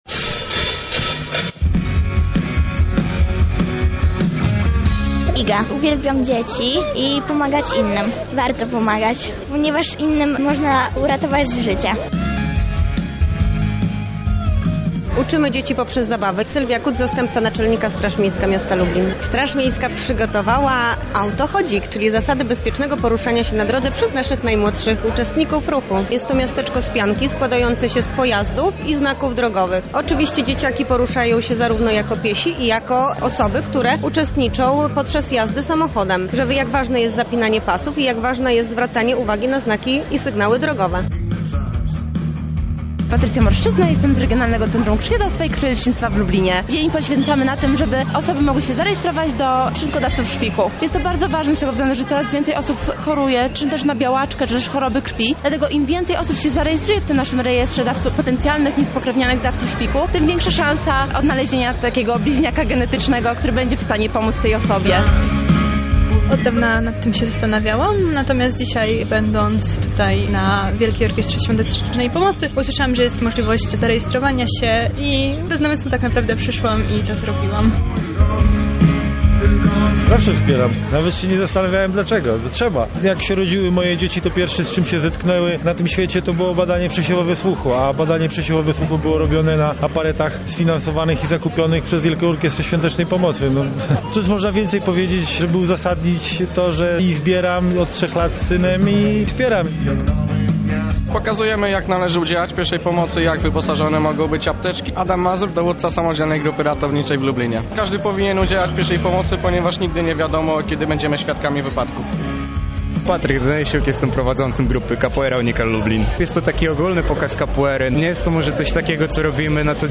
Na wczorajszym, lubelskim finale byli nasi reporterzy.